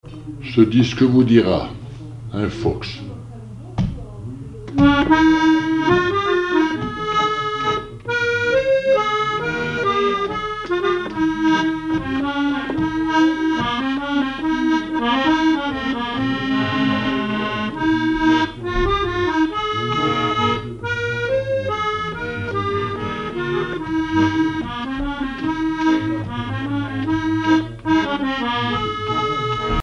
accordéon(s), accordéoniste
Flocellière (La)
danse : fox-trot
Répertoire à l'accordéon chromatique
Pièce musicale inédite